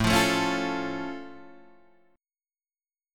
A+ chord {5 8 7 6 6 5} chord